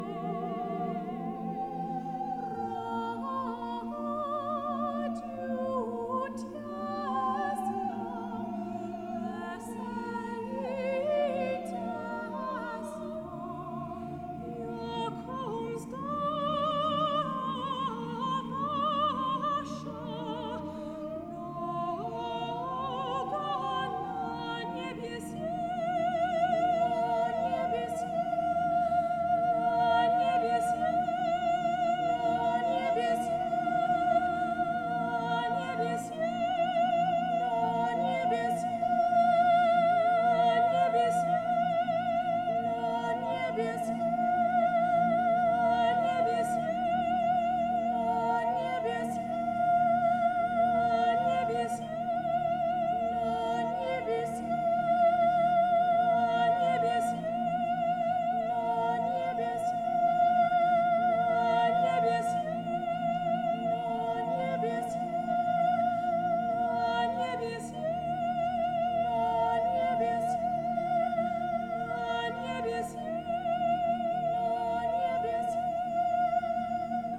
古典音樂/發燒天碟